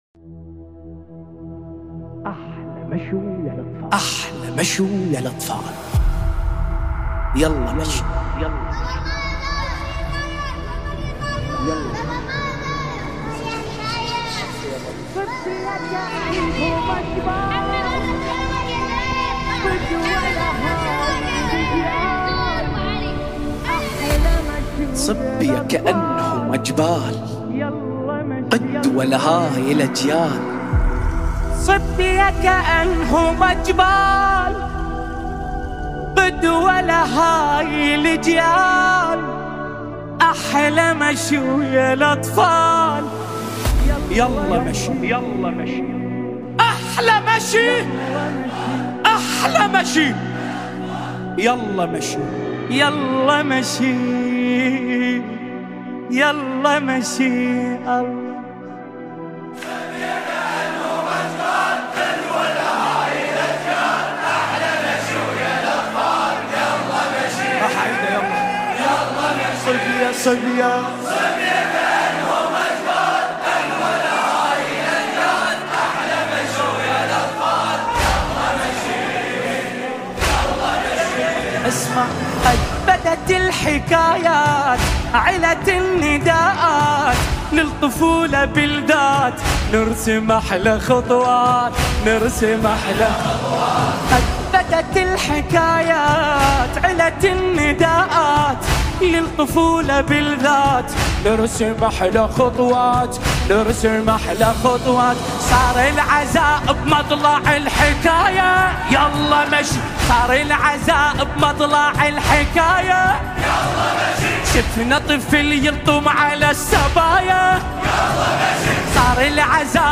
دانلود مداحی عربی
به مناسبت فرا رسیدن اربعین حسینی، نوحه دلنشین عربی